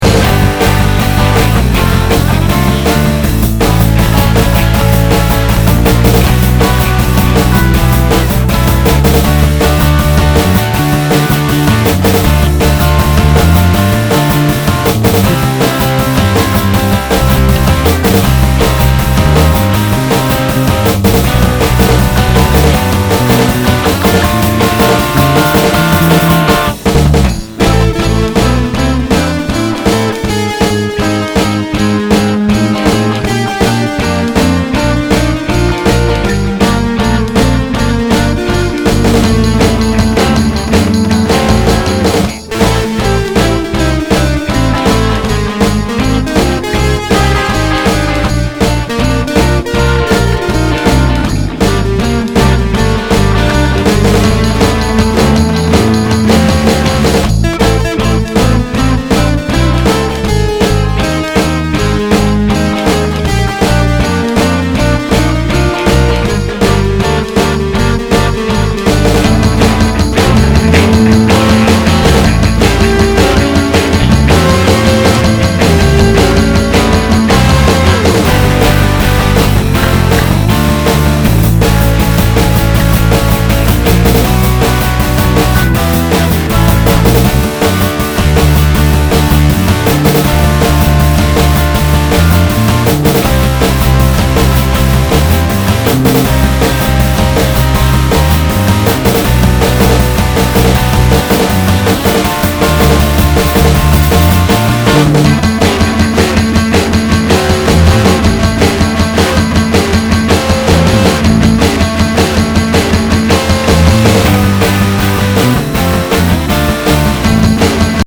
the skeleton-dancing ska-ish part sandwiched in ROCK